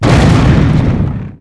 expl2.wav